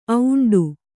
♪ auṇḍu